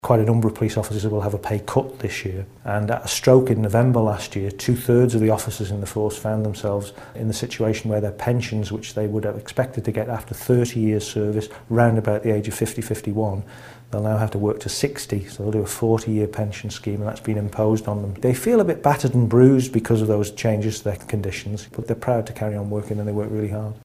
Speaking to TVIM News Gary Roberts explained the numerous changes to terms and conditions within the constabulary: